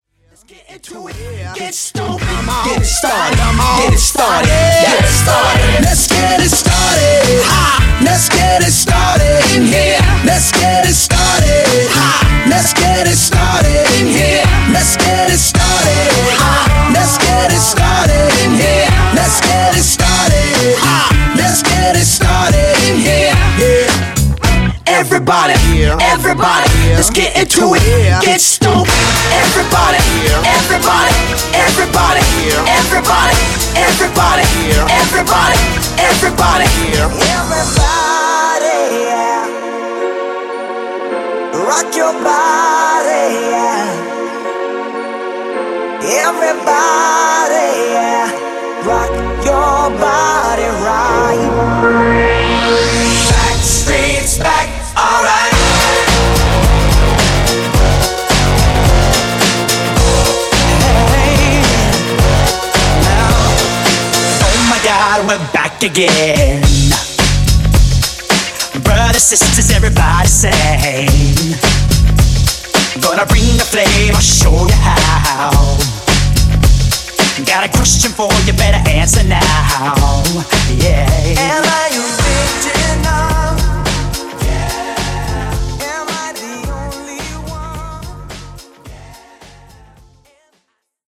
125 Dirty